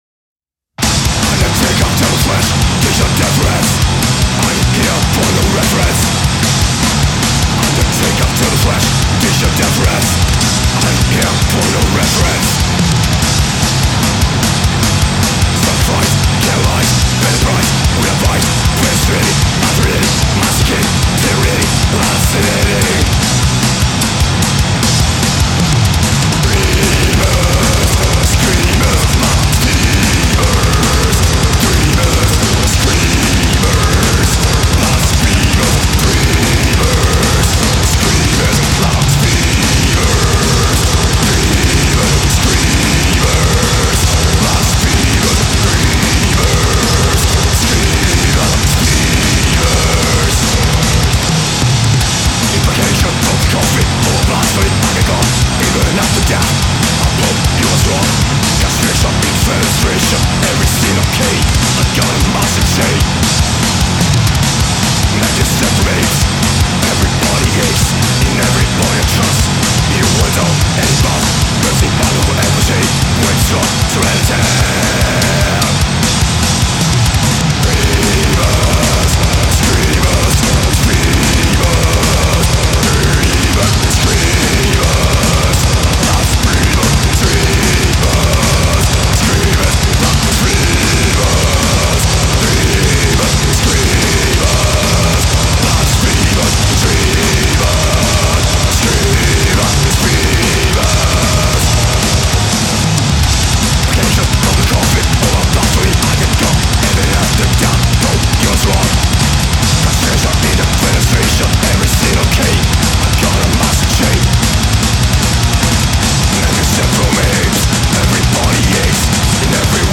Masters of Death Metal Darkness
demonic vocals
razor-sharp guitar riffs
thunderous bass lines
relentless drums
raw, unrelenting death metal power